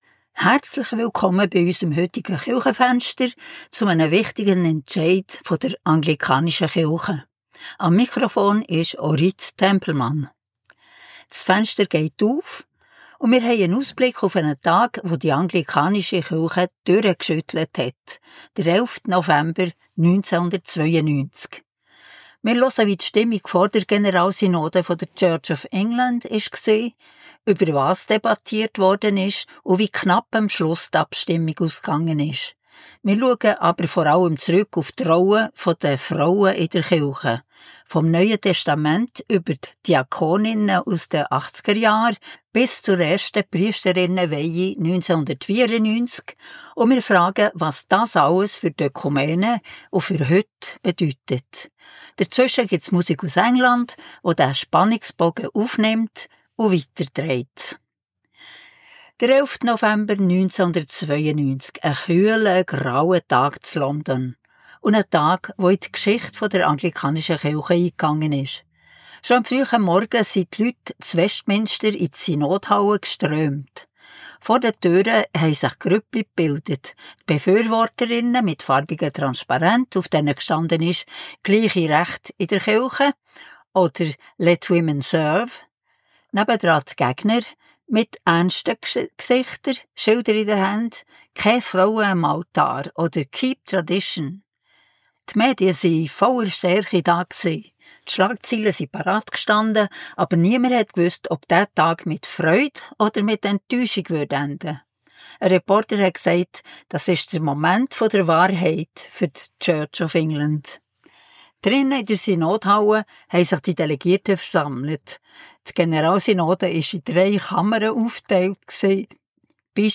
Die Sendung erzählt von den dramatischen Debatten, den ersten Priesterinnen und den Folgen bis heute, begleitet von Musik aus England.